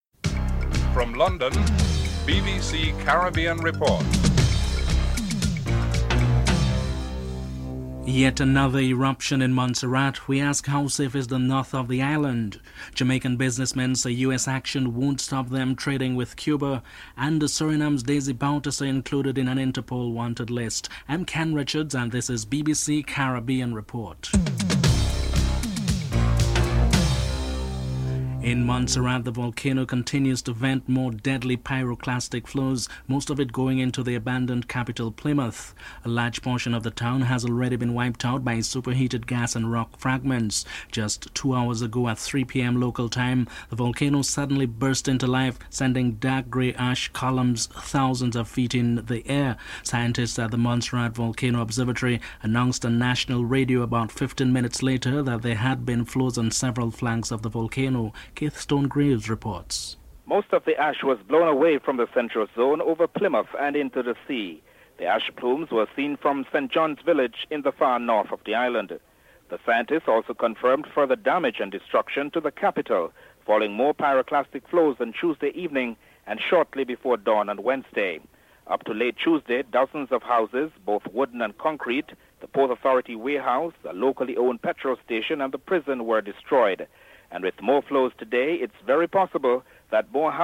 Jamaican businessmen are interviewed
Merlene Ottey and Deon Hemmings, Jamaican Athletes are interviewed.